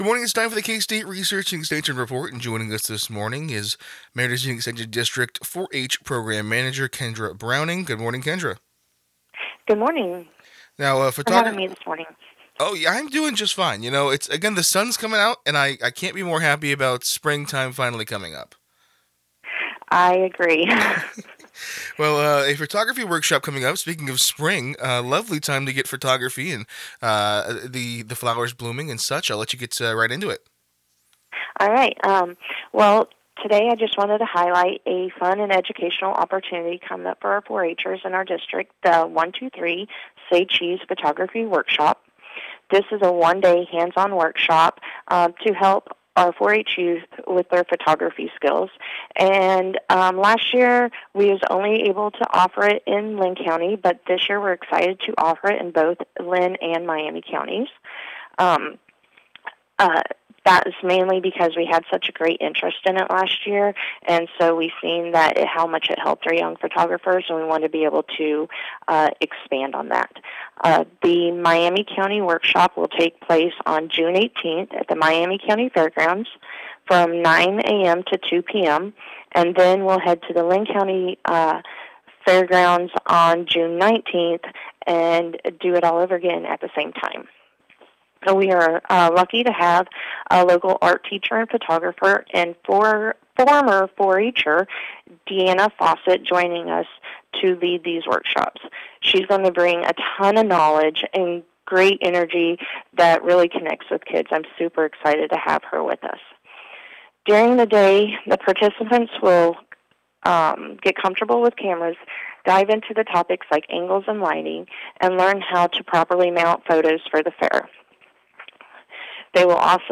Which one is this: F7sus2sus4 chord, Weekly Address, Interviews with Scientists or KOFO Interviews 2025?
KOFO Interviews 2025